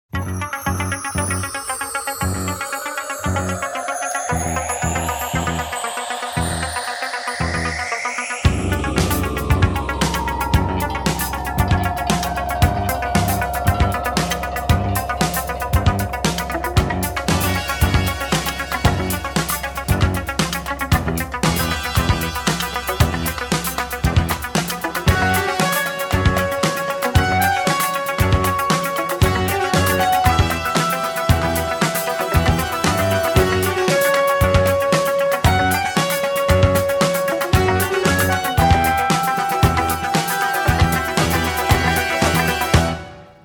восточные мотивы
Synth Pop
без слов
synthwave